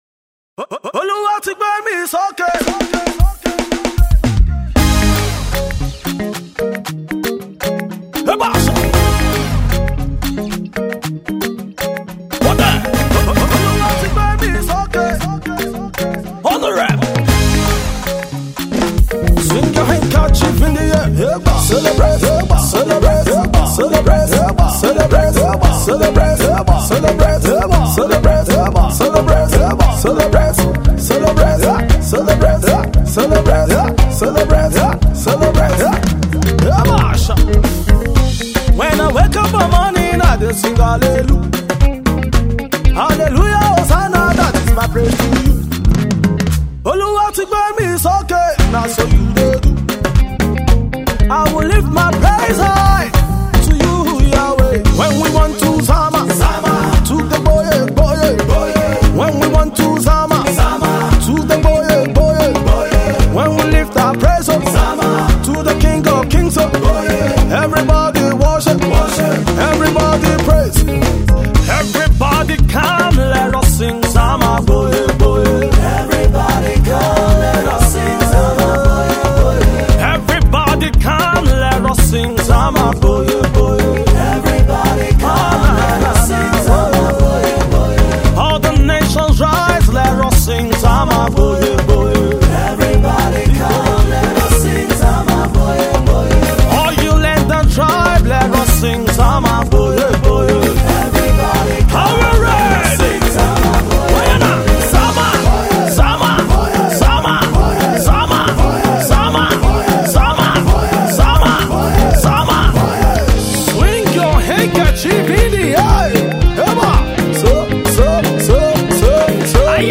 Eastern Nigerian based Gospel Singer